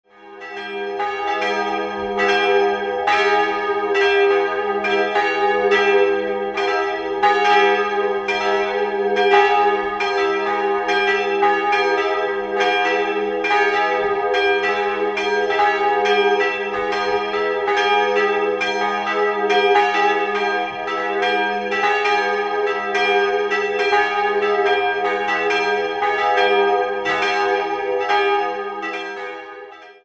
3-stimmiges Geläute: g'-es''-g''
99 cm um 1500 unbezeichnet, vermutlich Nürnberger Gießer
Ein Geläute, das aufgrund der ausgefallenen Tonfolge einen hohen Wiedererkennungswert hat.